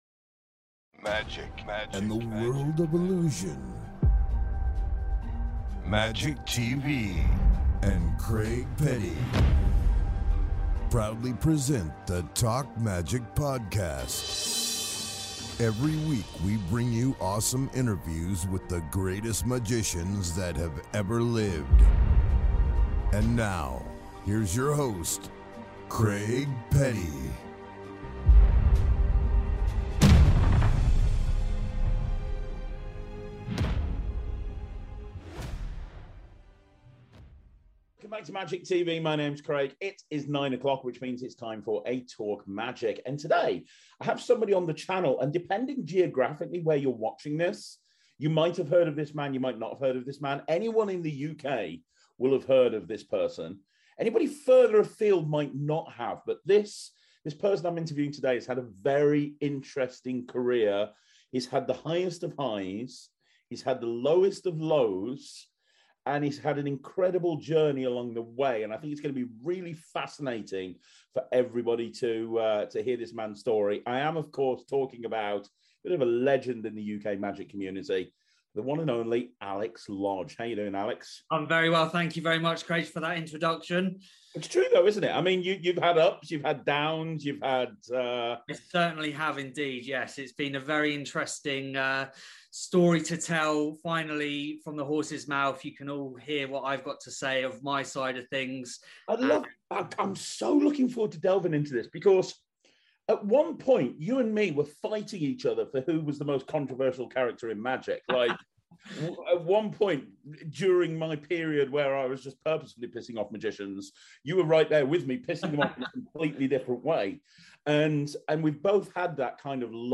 This interview is unlike any interview you have seen before.